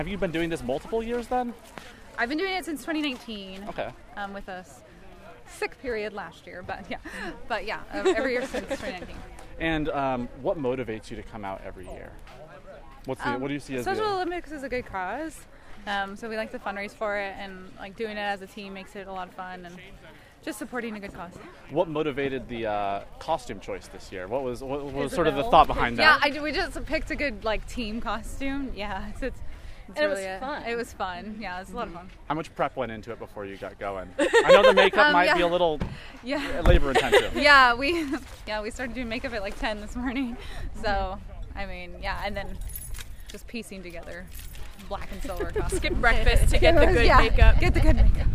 KMAN caught up with the dispatchers team following the plunge, listen to their remarks below: